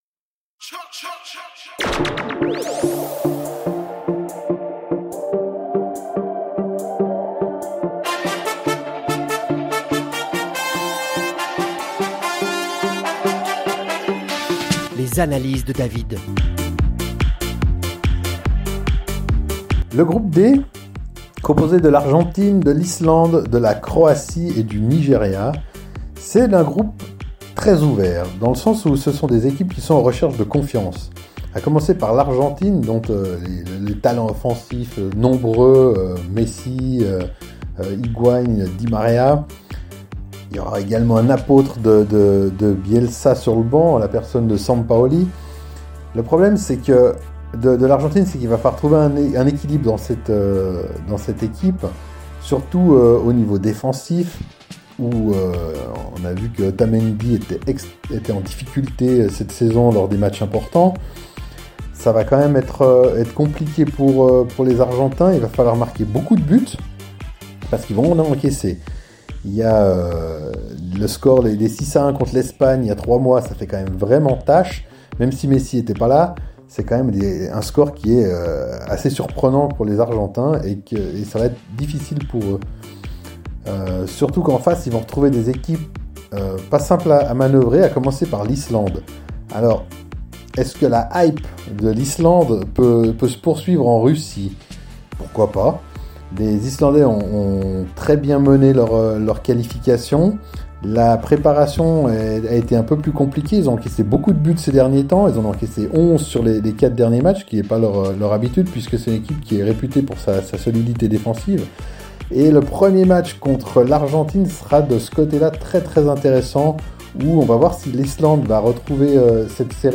avec sarcasme et polémique.